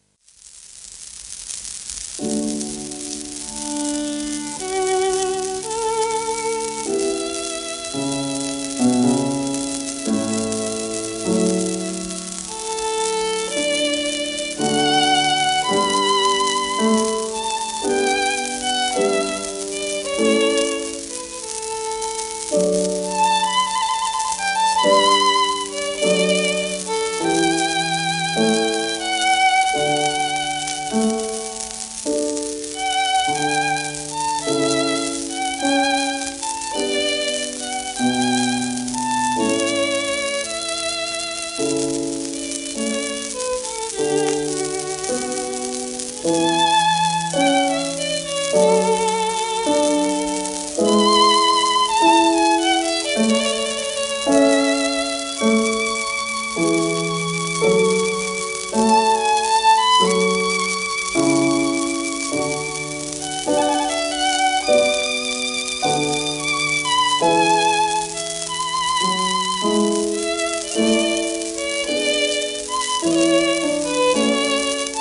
シェルマン アートワークスのSPレコード